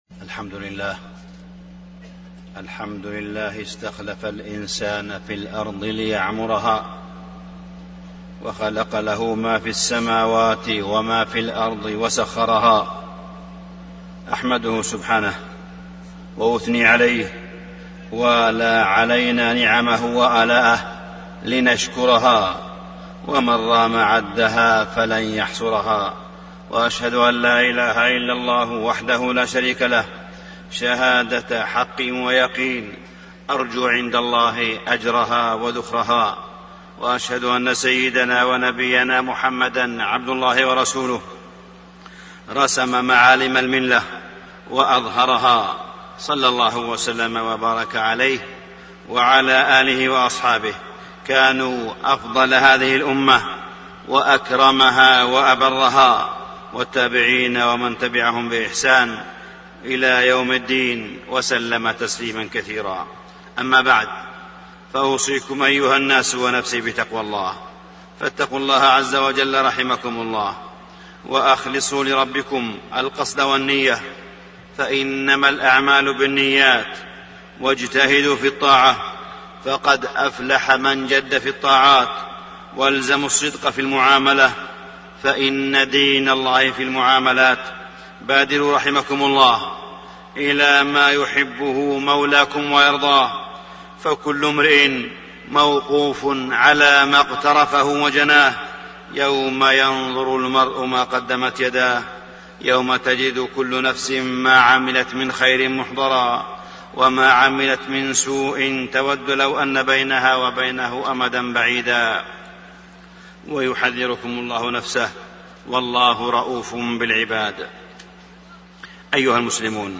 تاريخ النشر ١٦ شعبان ١٤٣٣ هـ المكان: المسجد الحرام الشيخ: معالي الشيخ أ.د. صالح بن عبدالله بن حميد معالي الشيخ أ.د. صالح بن عبدالله بن حميد الإحسان